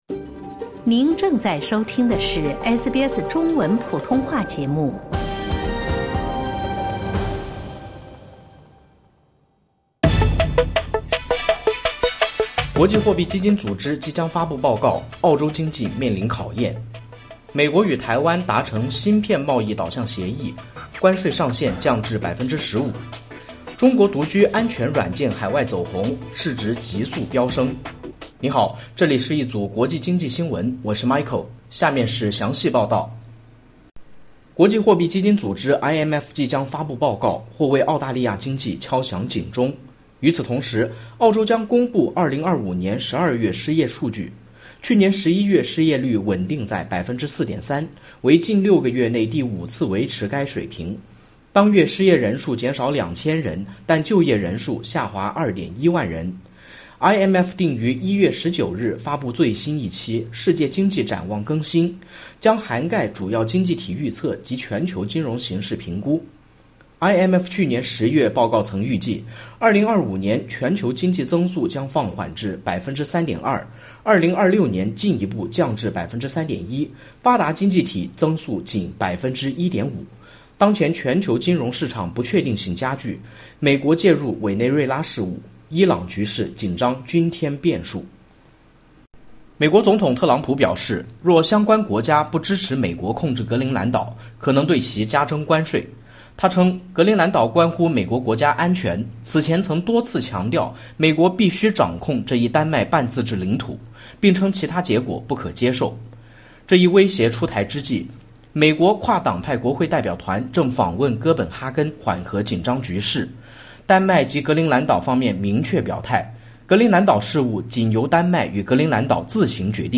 点击音频收听报道